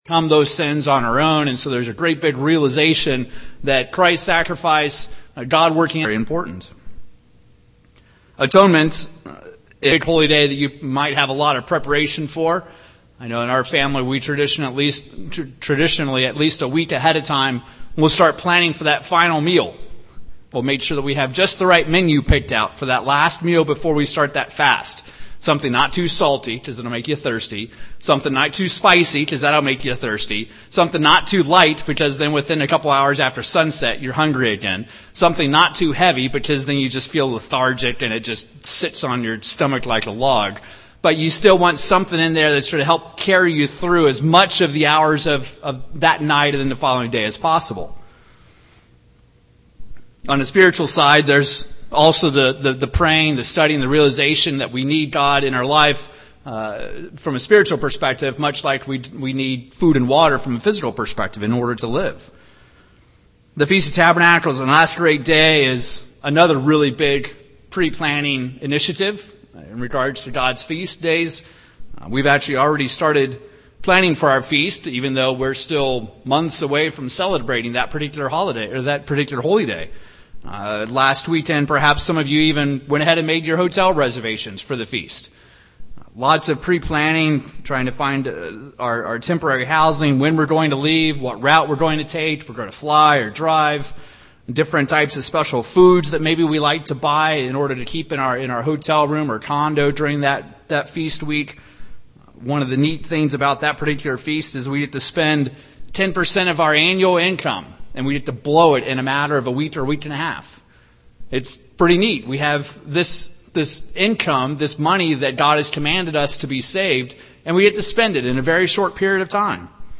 Given in Wichita, KS
UCG Sermon Studying the bible?